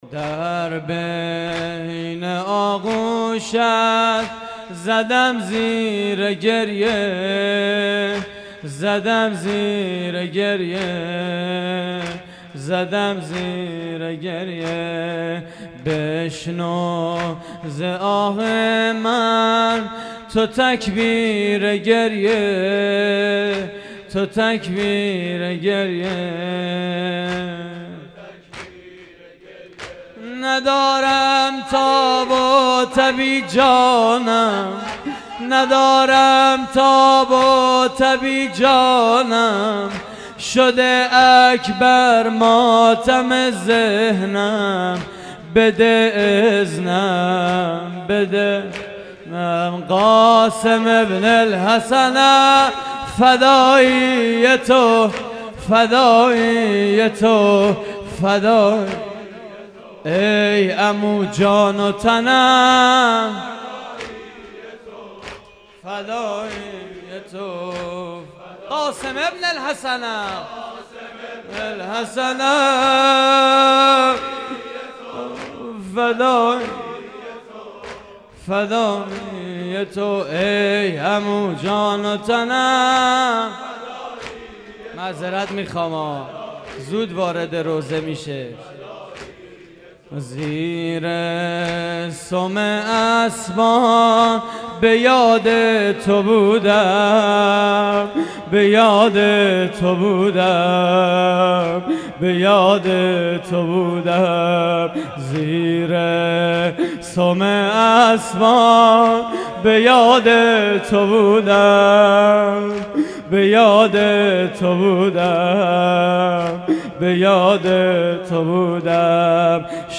مراسم عزاداری شب ششم ماه محرم / هیئت کانون دانش آموزی امام حسن مجتبی (ع) - نازی‌آباد؛ 20 آذر 89